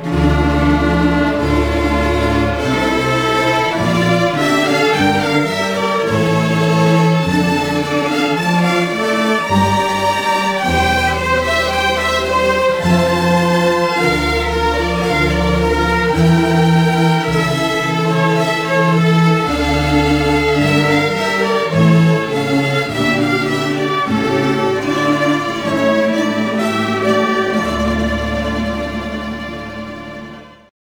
interpretado por una rondalla
Barroco
bandurria
cuerda
guitarra
laúd
rondalla
rondó